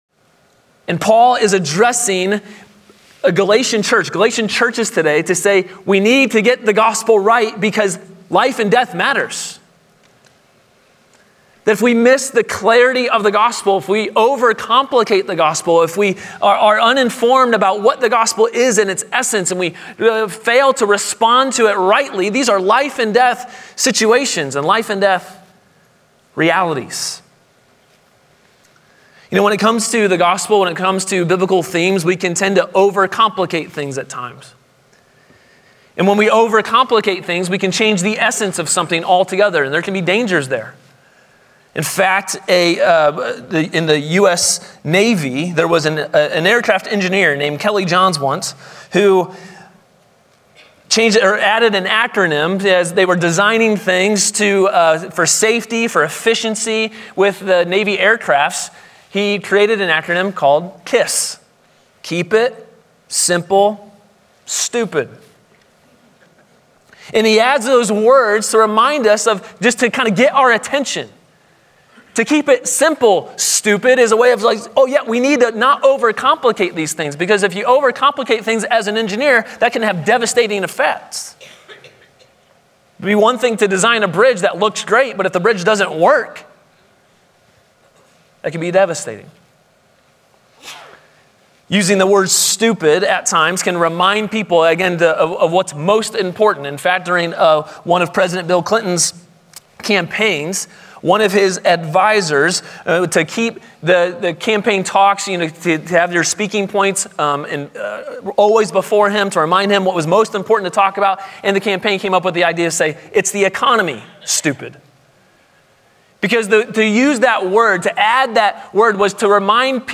A sermon from the series "Freedom in the Gospel." Pursue the mission by preserving the truth.